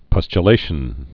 (pŭschə-lāshən, pŭstyə-)